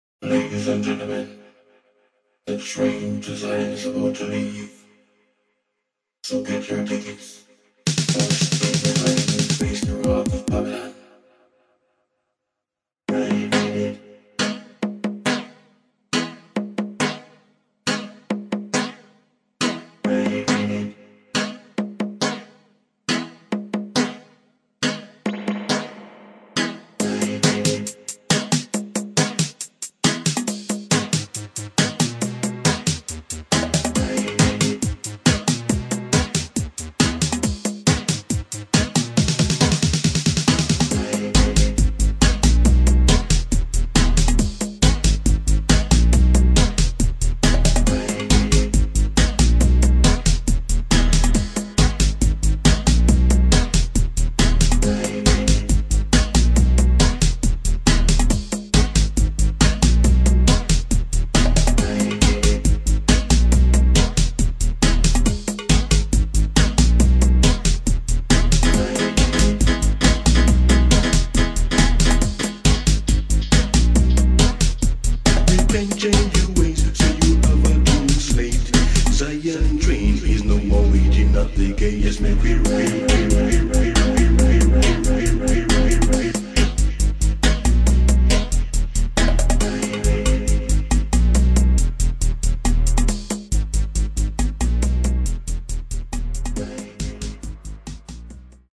[ DUB / REGGAE ]